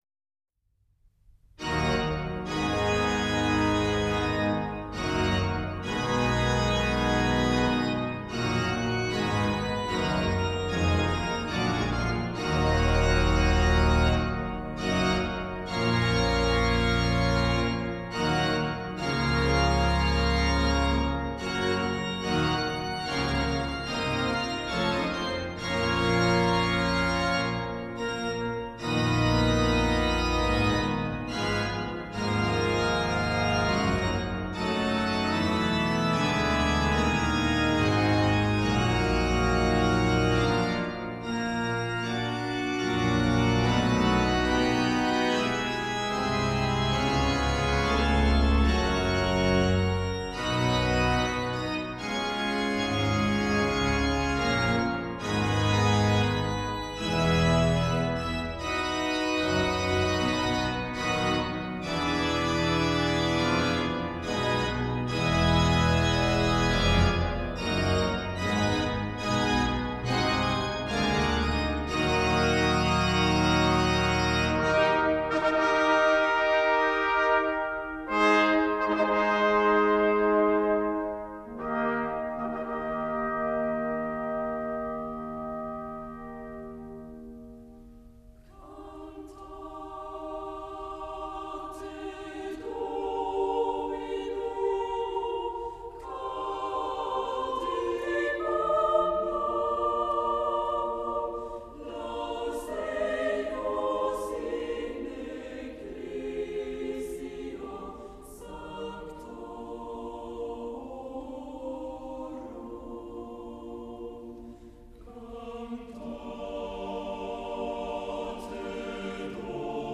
里面收录有15首歌曲，这些歌曲是瑞典的一个教堂的唱诗班演唱。
总之，这是一张录音完美，音乐动人的经典录音。
它的质感极其强烈，音浪就像涨潮时的余波，有序的“层层压上”（而不是一股脑儿的涌向岸边）。